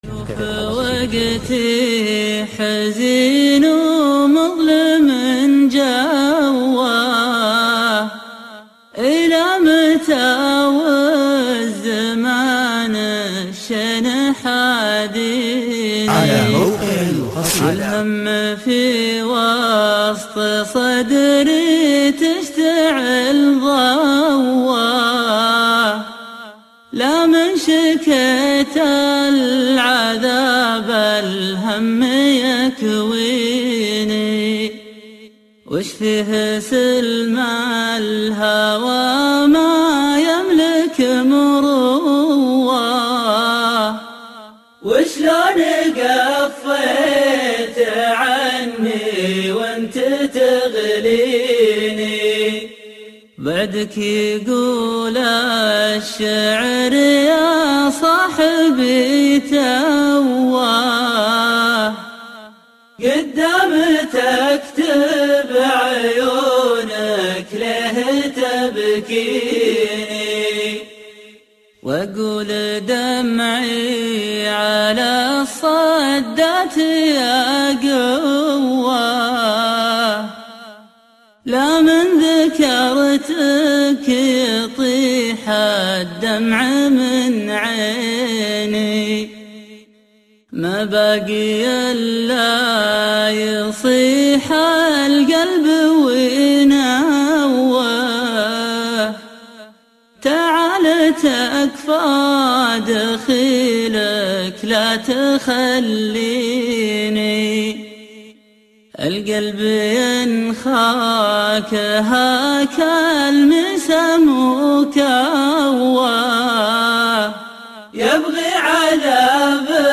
شيله